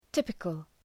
{‘tıpıkəl}